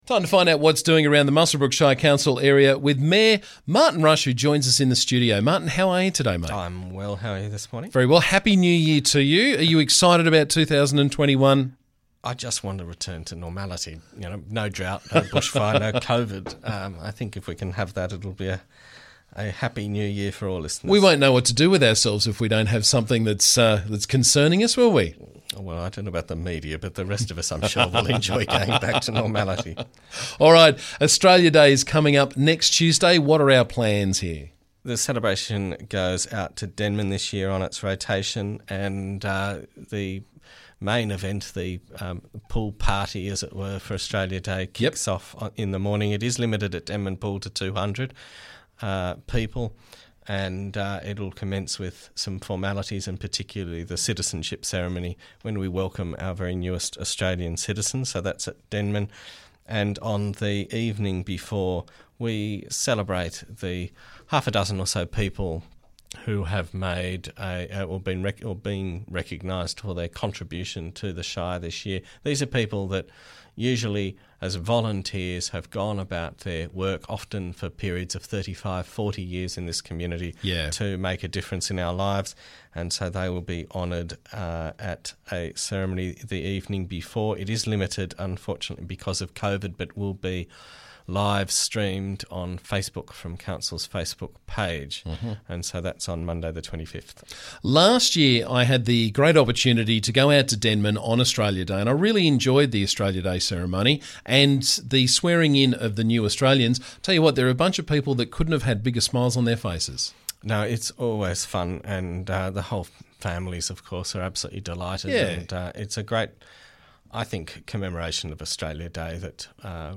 Muswellbrook Shire Council Mayor Martin Rush joined me to talk about the latest from around the district.